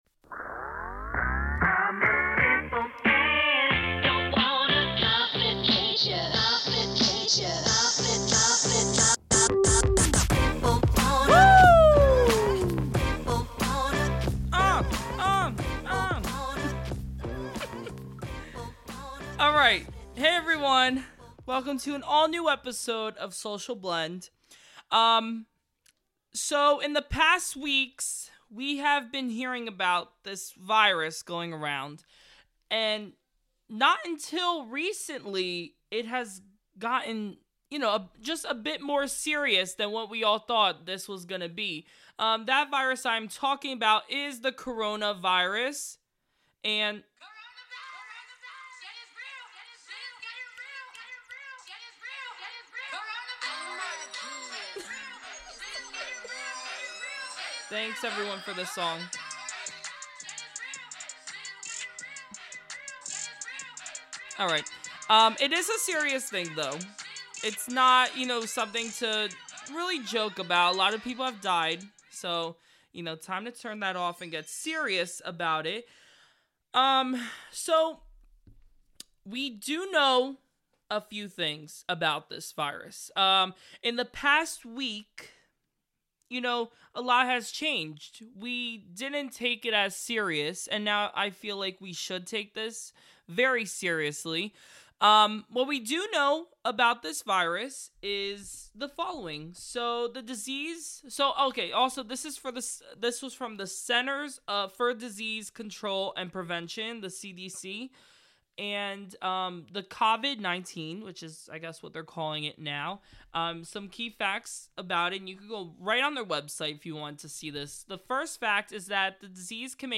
takes calls from friends, family, and fans about this matter, and how they are dealing with these times.